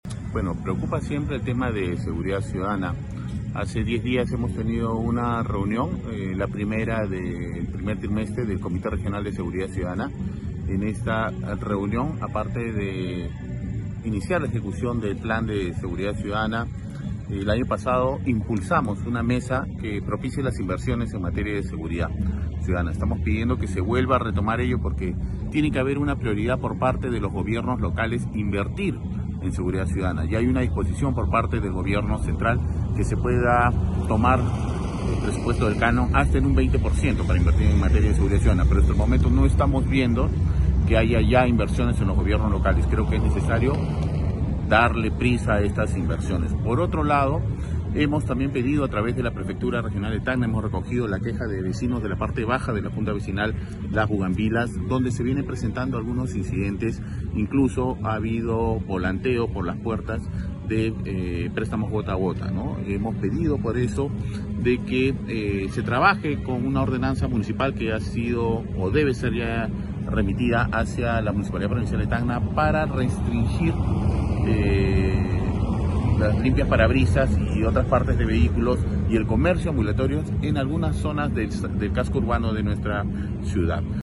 06.-PREFECTO-REGIONAL-SE-PRONUNCIA-ANTE-INSEGURIDAD-CIUDADANA.mp3